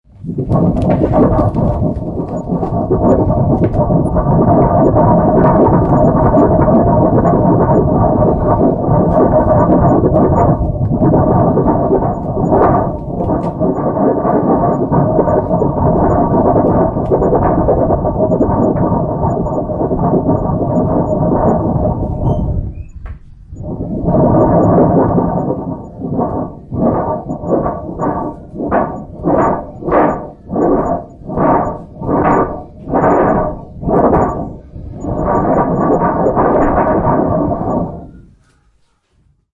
Lastra打击乐雷鸣
描述：雷声打击乐 lastra管弦乐器
Tag: 风暴 天气 打击乐器 闪电 Lastra的 霹雳